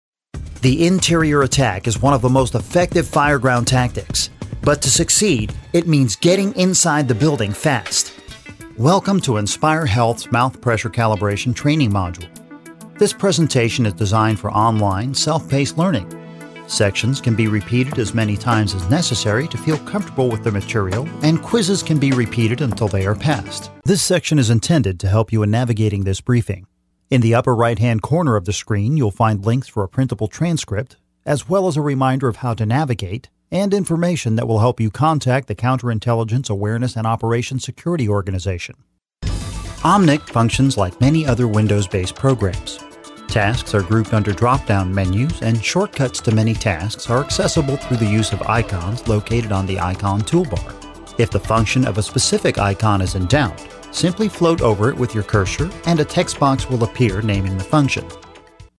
A versatile Voice Actor who takes direction well and can also deliver a variety of unique character voices.
Conversational, guy next door, humorous, enthusiastic, character, youthful, caring.
Sprechprobe: eLearning (Muttersprache):